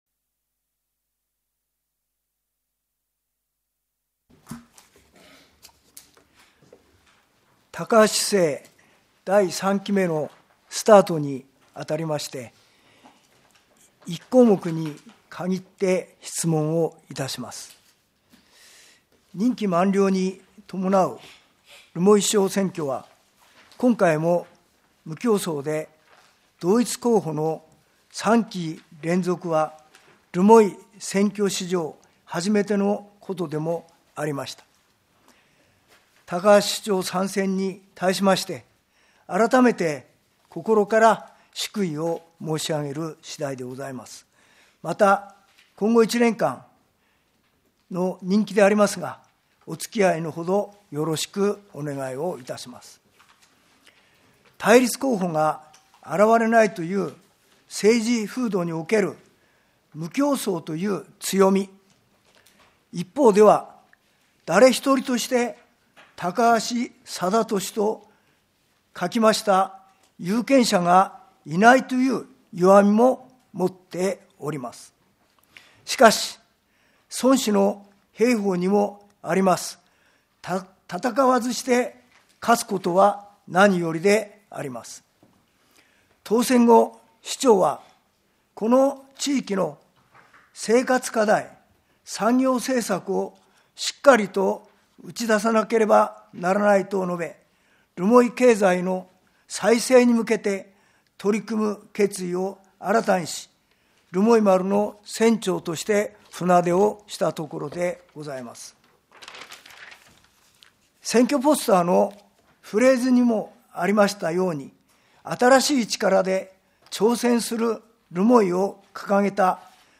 議会録音音声